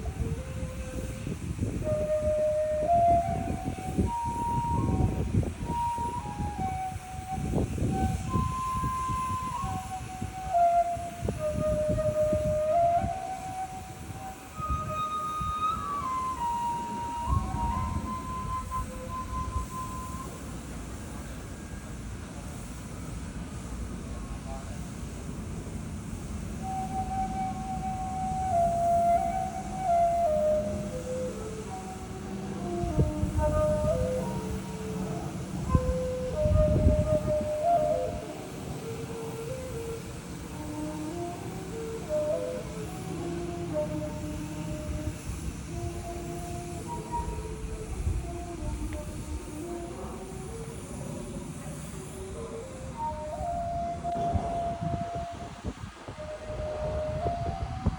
Flute Player at Giant Buddha
Baghua Hill in the Evening